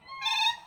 Contact Call | A soft, purring call expressing reassurance and location.
Siberian-Crane-Contact.mp3